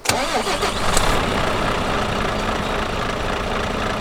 Index of /server/sound/vehicles/lwcars/truck_daf_xfeuro6
startup.wav